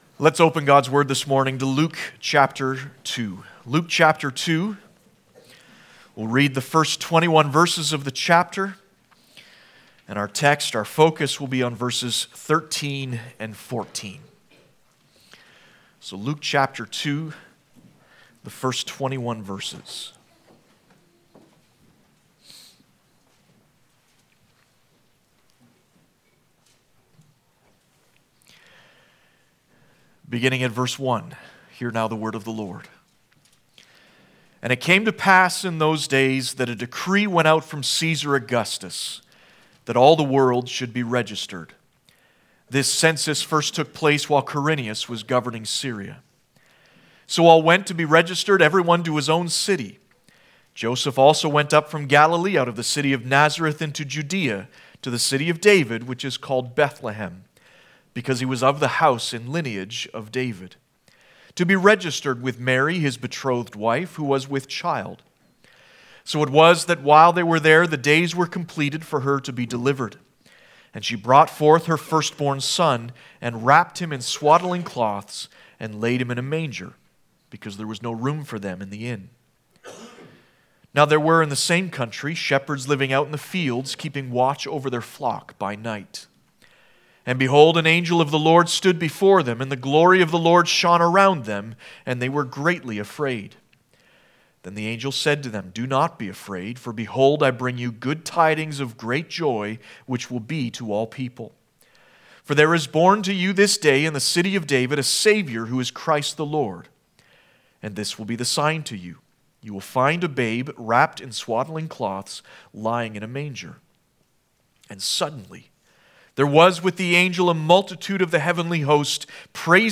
Service Type: Christmas